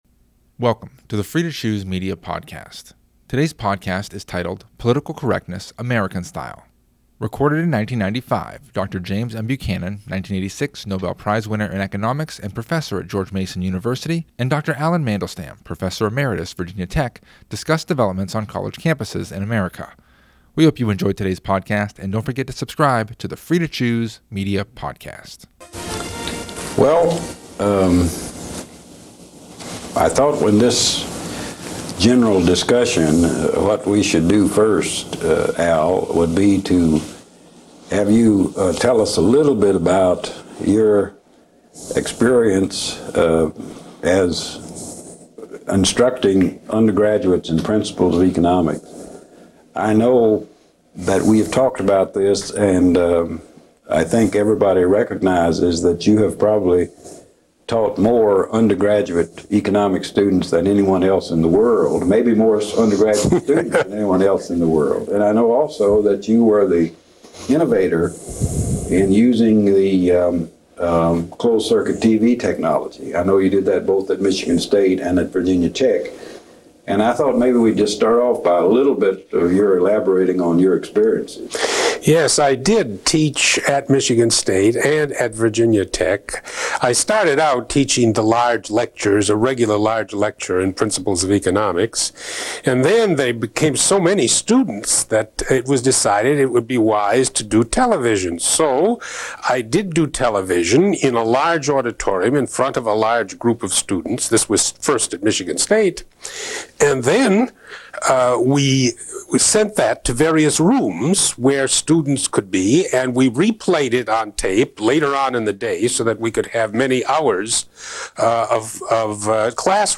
Recorded in 1995